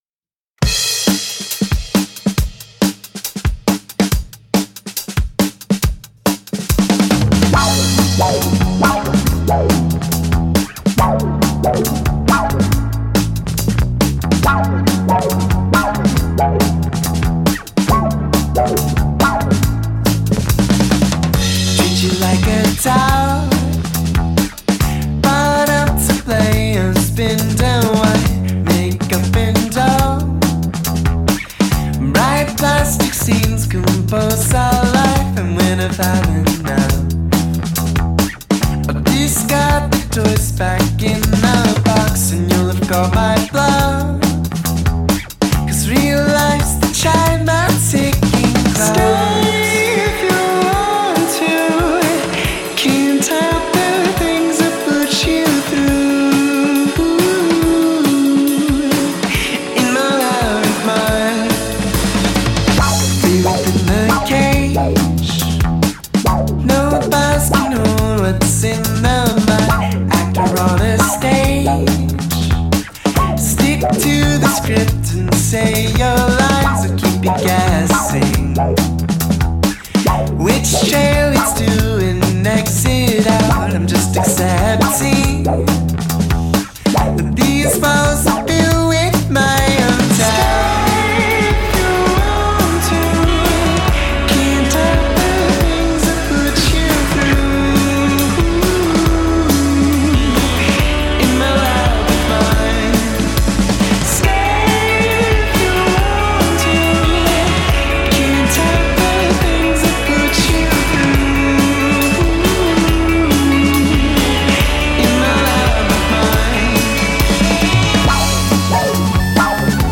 dream-pop